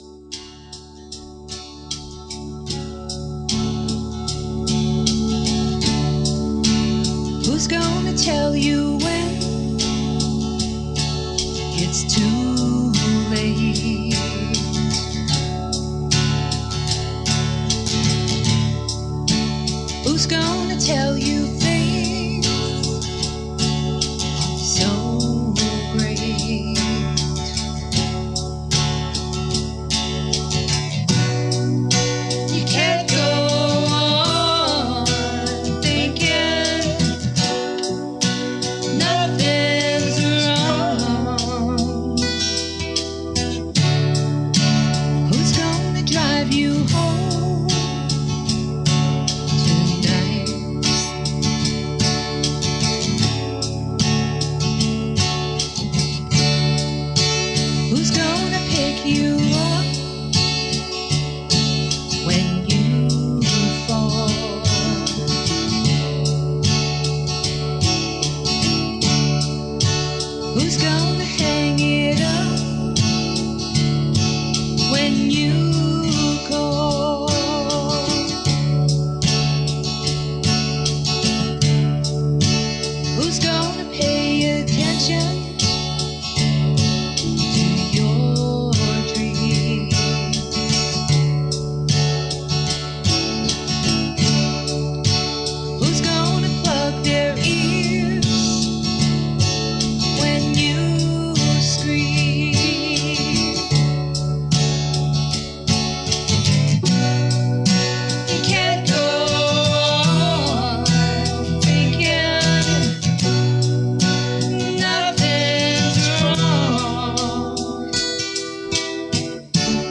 keys
vocals
guitar
beautifully laid out, so smooth , well done